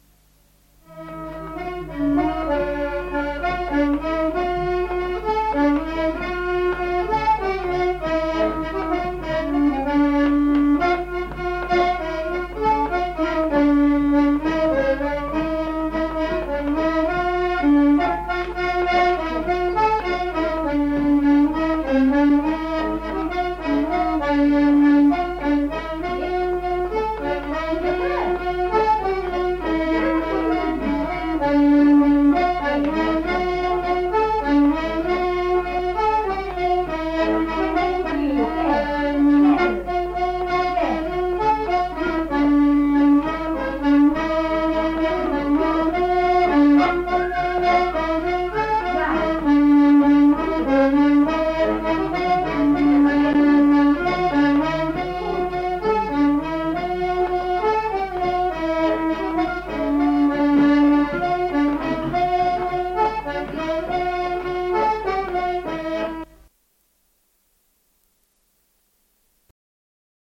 Valse
Aire culturelle : Astarac
Lieu : Pouy
Genre : morceau instrumental
Instrument de musique : accordéon diatonique
Danse : valse